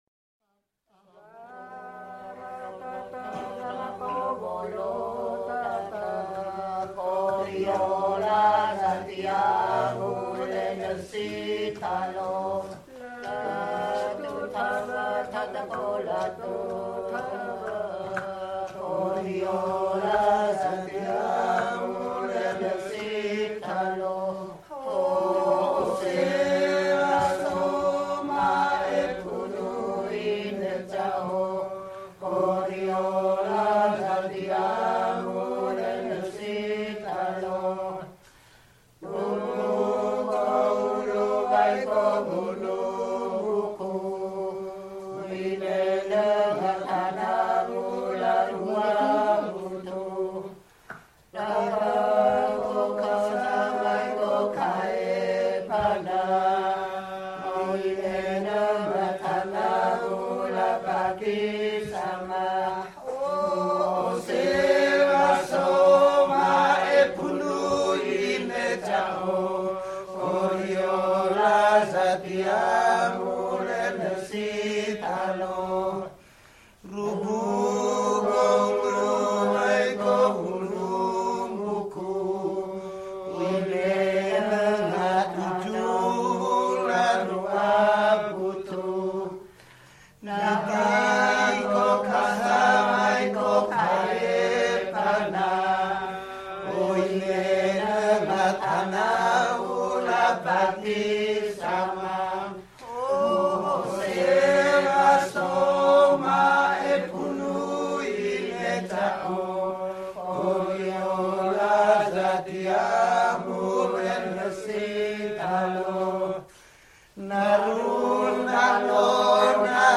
with the rest of the group singing as chorus.
2010 … Orang Ngada Acappela Group singing the final take of another seuaze
orang-ngada-acappela-group-singing-the-final-take-of-another-seuaze.mp3